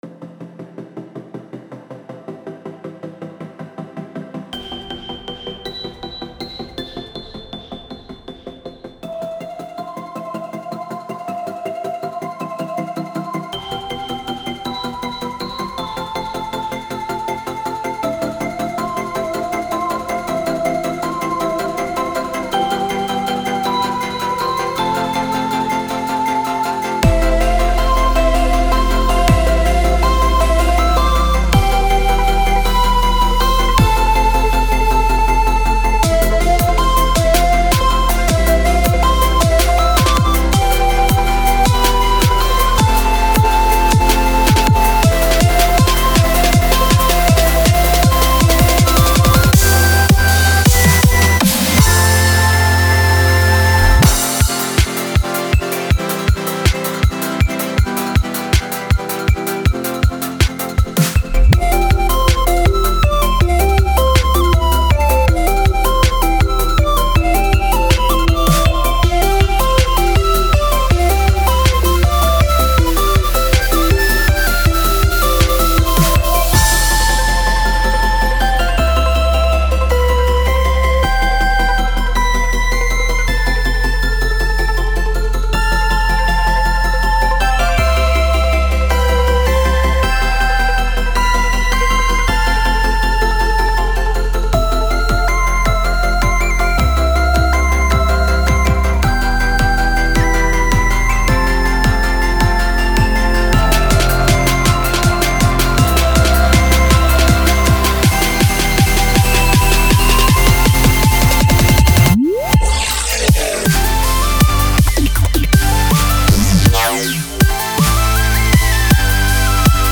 Super polished sound
Dance